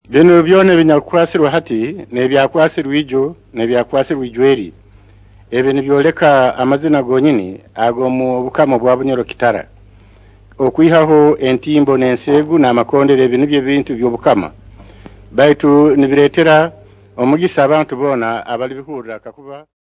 2. Speech TR133-02.mp3 of Speech by the Mukama